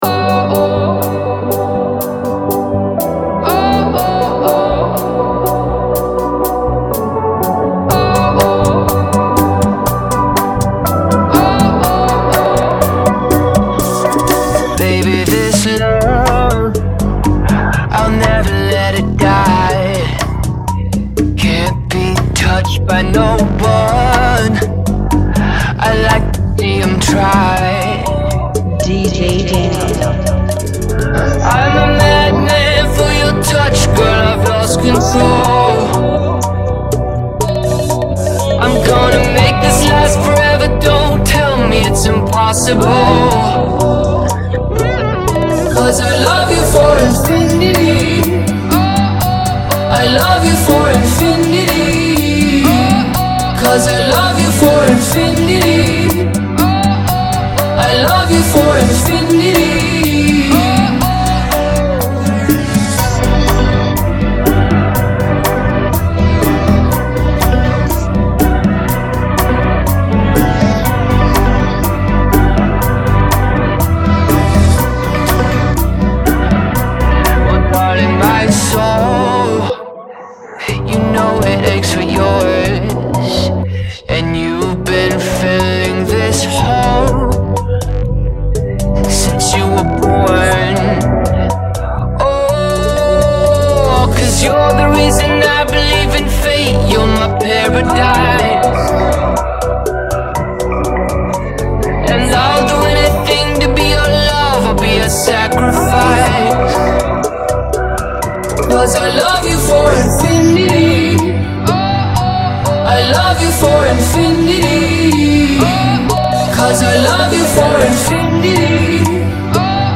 122 BPM
Genre: Bachata Remix